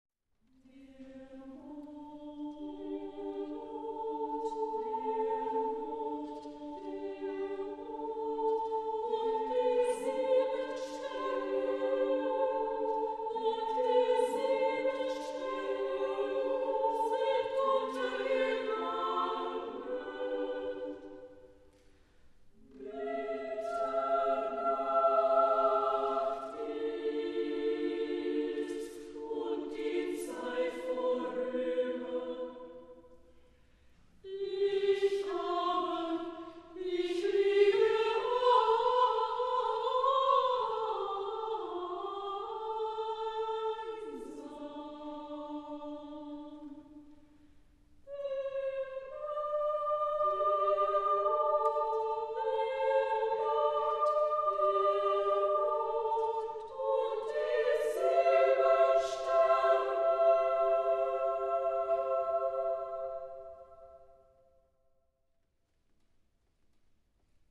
... Fragmente für Mädchenchor, Querflöte und Violoncello ...
Genre-Stil-Form: zeitgenössisch ; weltlich
Chorgattung: SAA  (3 Mädchenchor Stimmen )